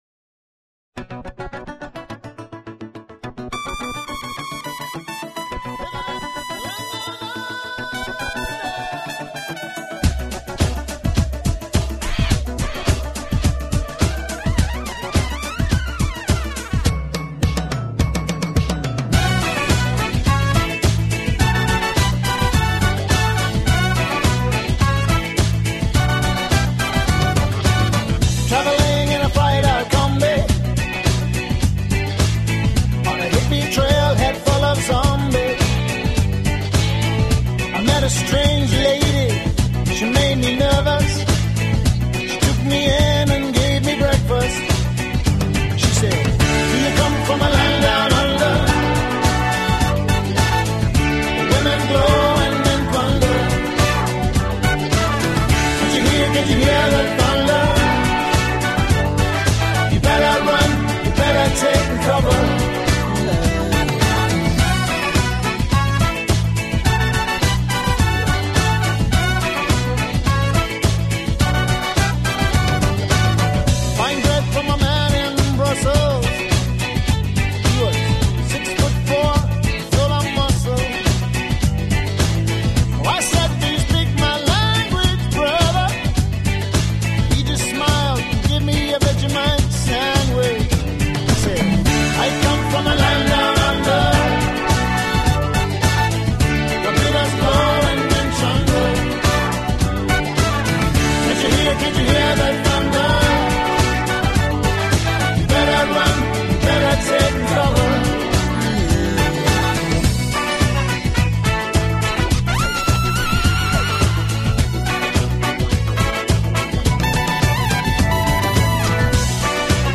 Tema Musical 4 min. 45 sg.